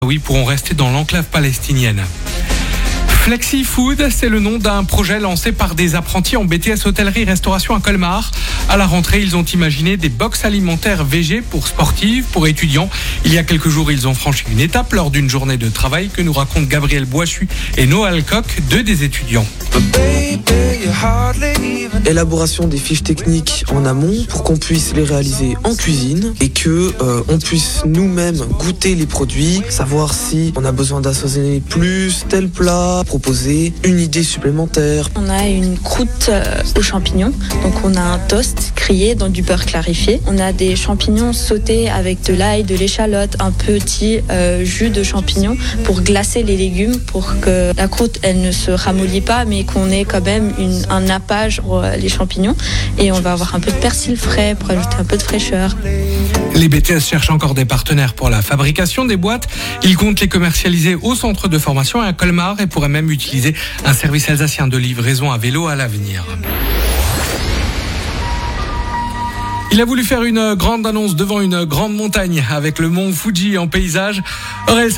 Interview